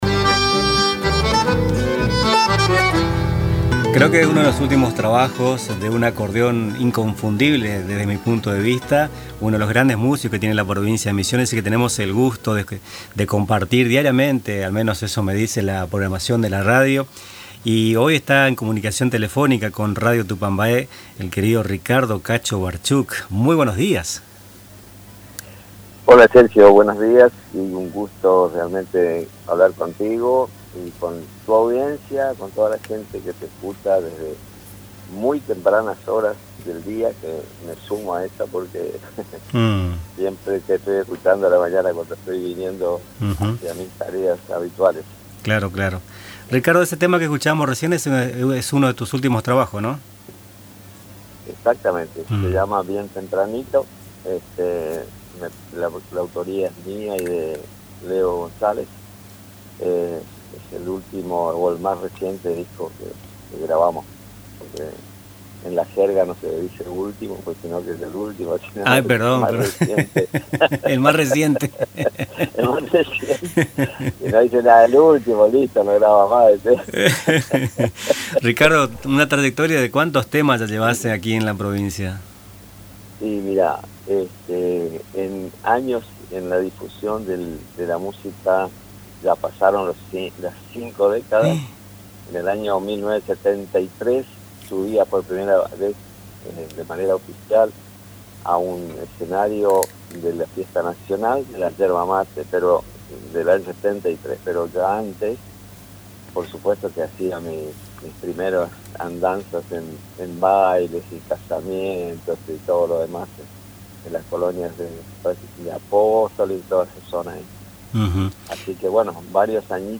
La entrevista de hoy buscó conocer un poco más a este carismático músico de Misiones, que a lo largo de su trayectoria fue cosechando amigos y aplausos. Además, adelantó la presentación de un nuevo material hacia fin de año. El músico realizó una invitación para este viernes en Sala Tempo, donde se realizará una presentación musical.